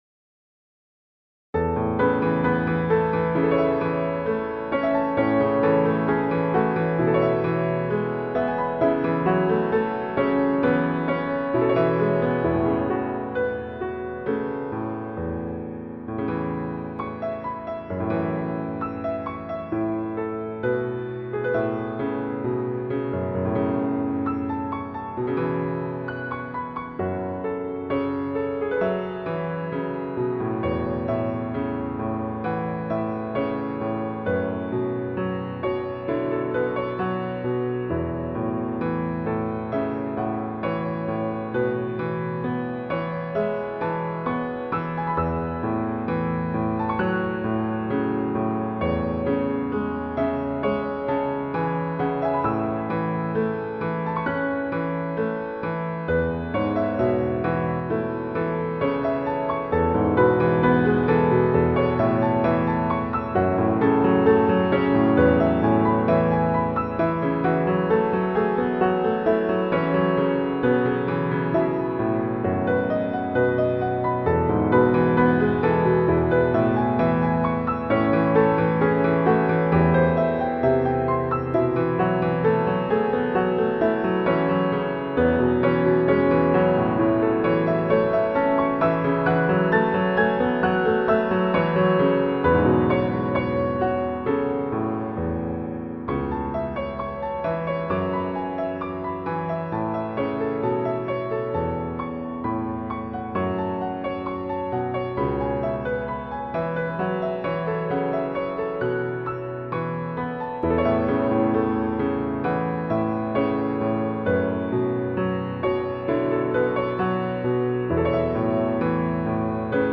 A调伴奏：